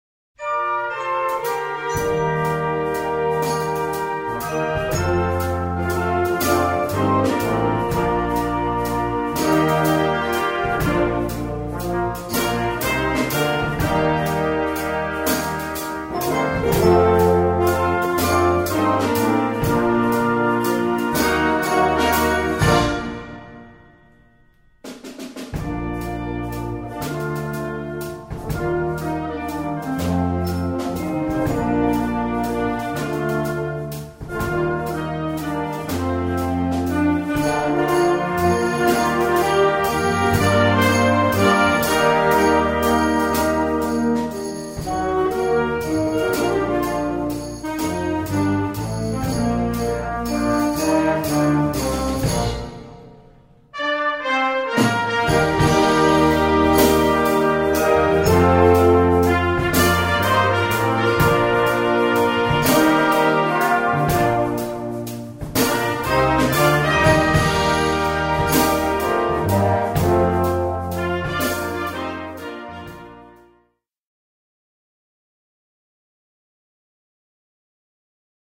Gattung: Konzertwerk
Besetzung: Blasorchester
Eine schöne Ballade